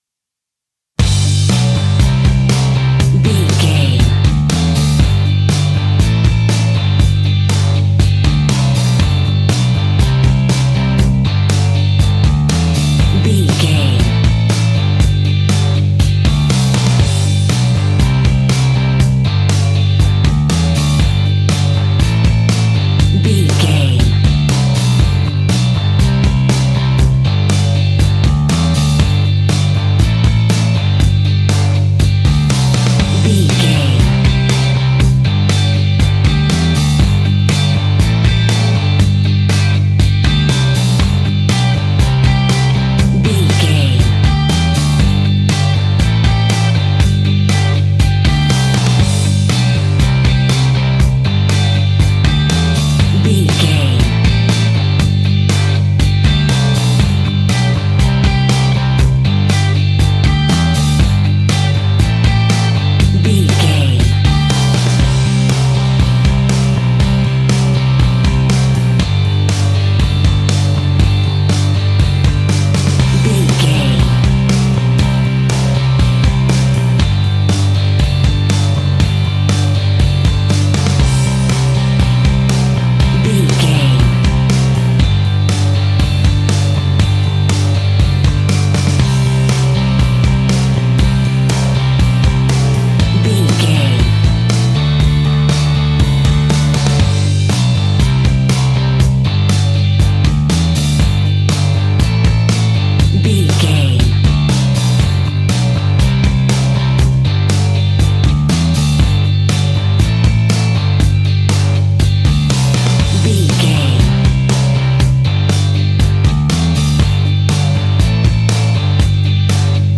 Ionian/Major
energetic
uplifting
upbeat
groovy
guitars
bass
drums
piano
organ